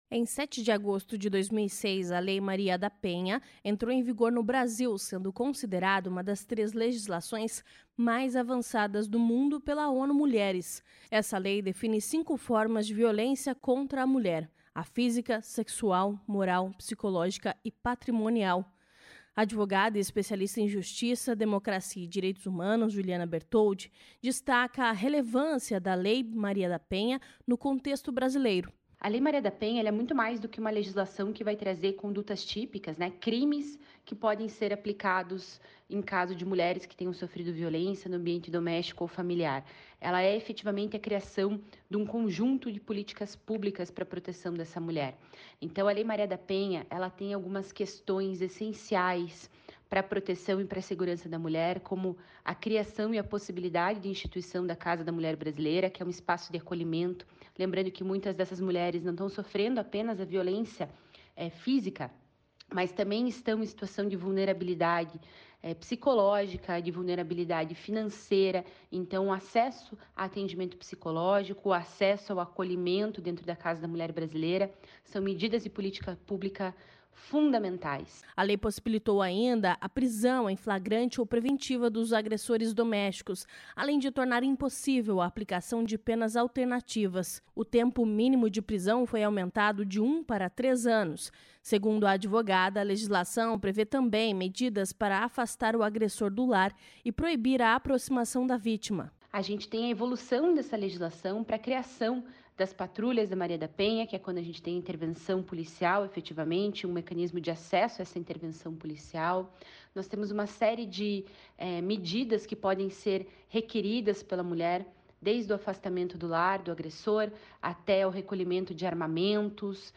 Segundo a procuradora da Mulher na Assembleia Legislativa, deputada Cloara Pinheiro (PSD), em meio a esses desafios, o órgão tem o trabalho de defender os direitos da mulher.